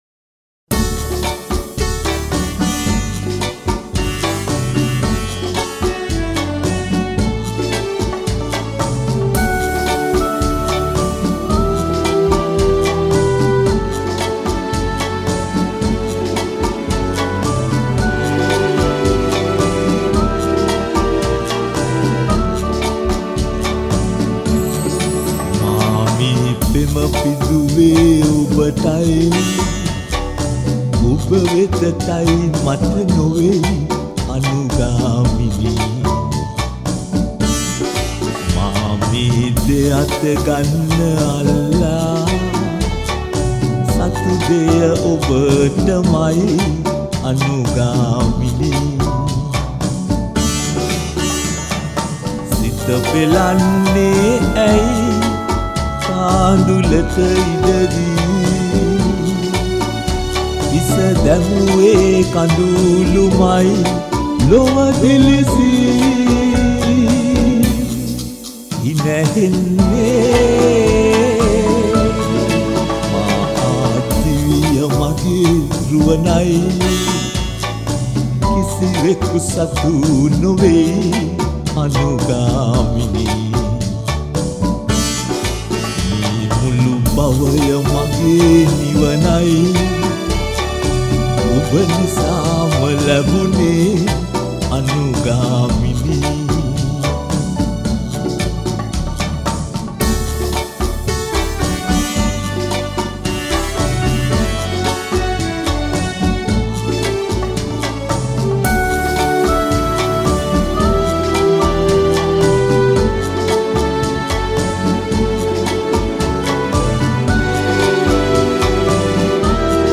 Sinhalese songs